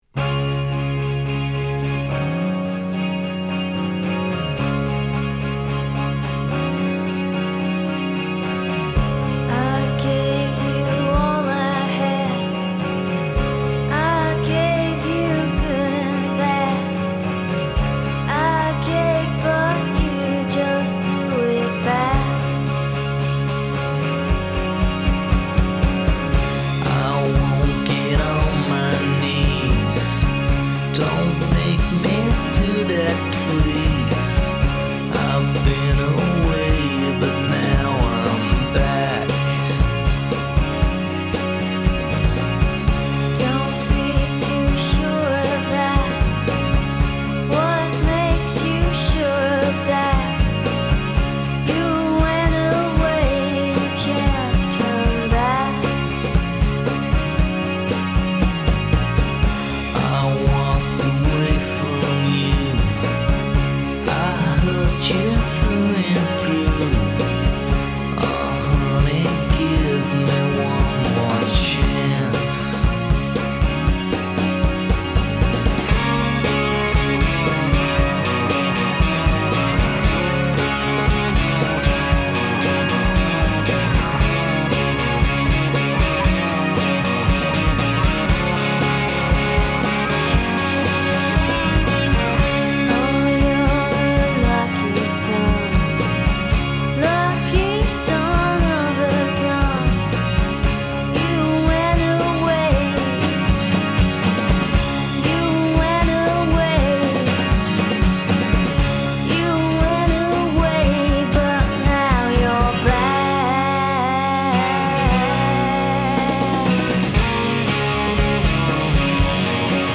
Genre: Indie Rock.